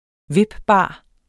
Udtale [ ˈvibˌbɑˀ ] Betydninger som kan vippes